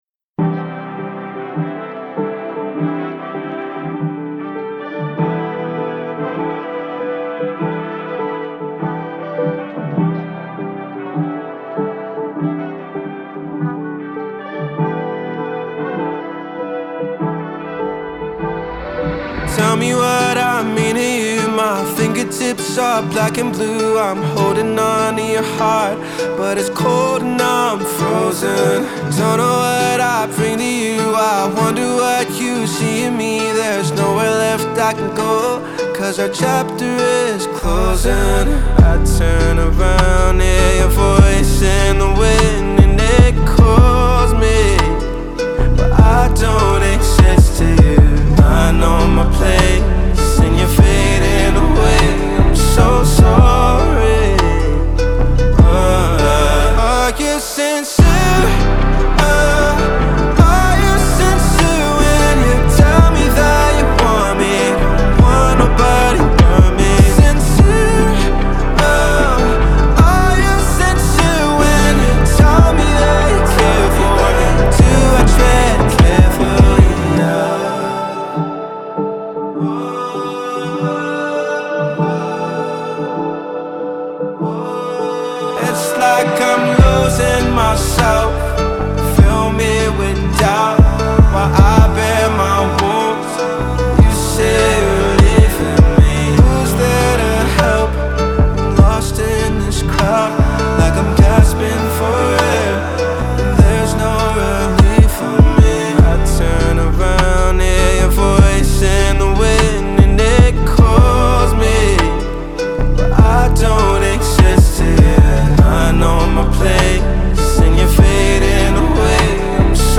• Жанр: Soul, R&B